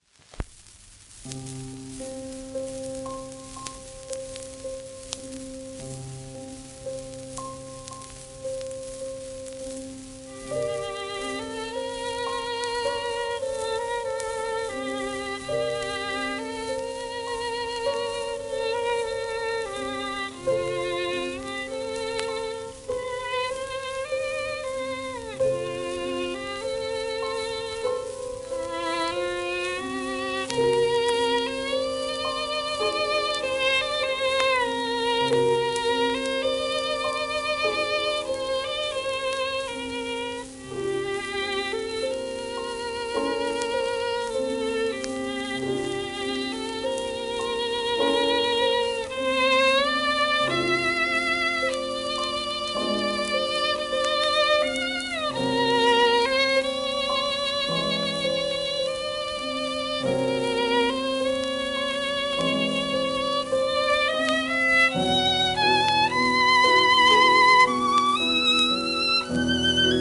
盤質A-/B+ *小キズ,薄いスレ,エッヂチップ